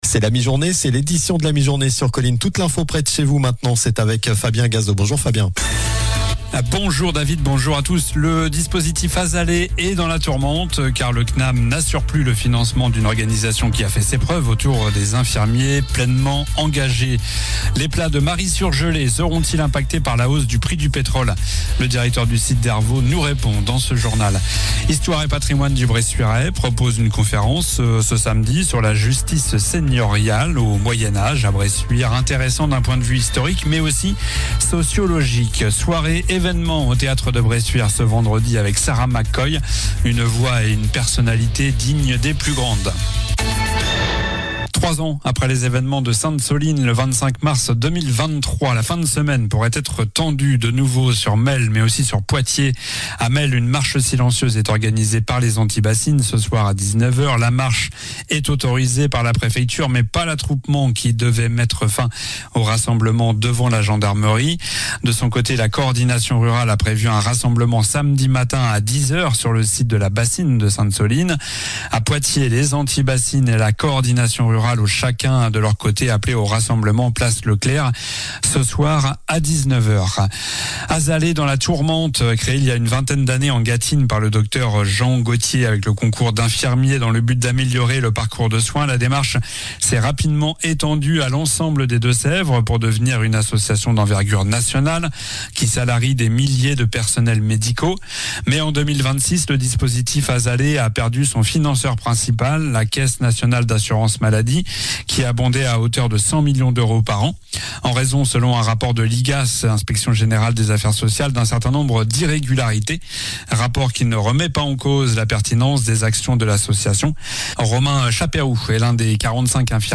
Journal du mercredi 25 mars (midi)